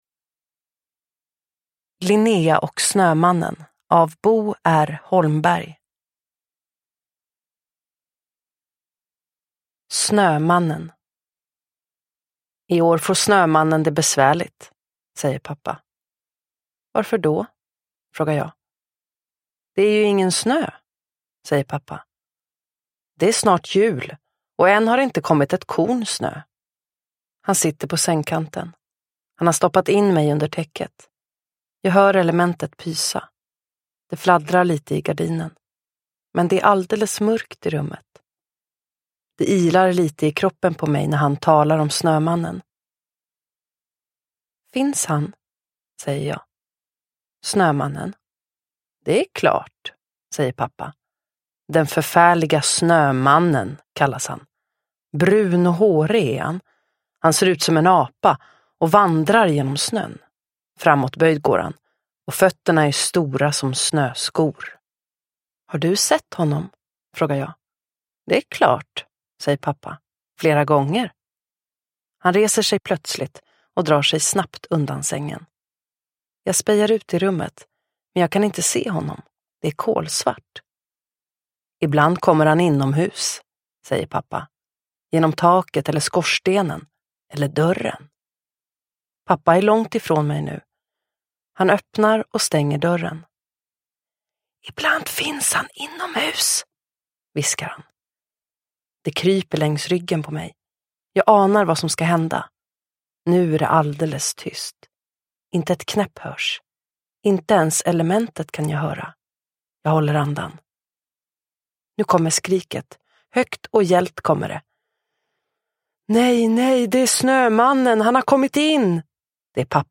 Linnea och snömannen – Ljudbok – Laddas ner